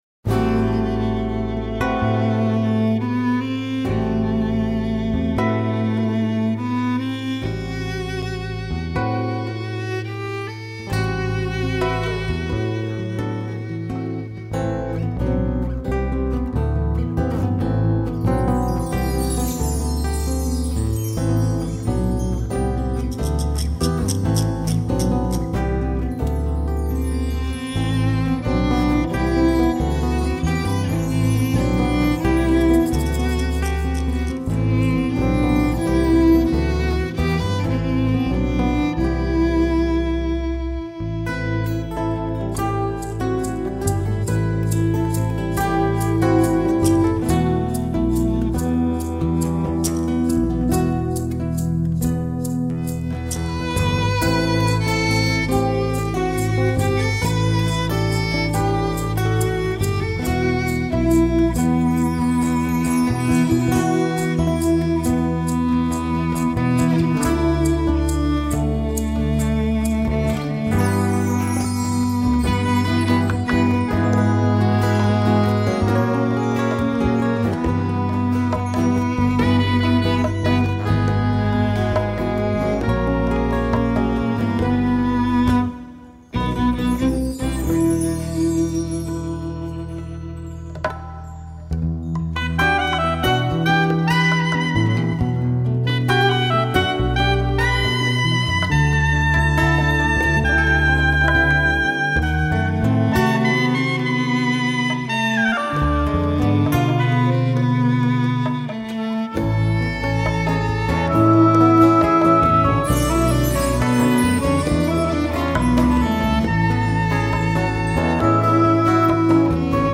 2850   05:34:00   Faixa: 8    Mpb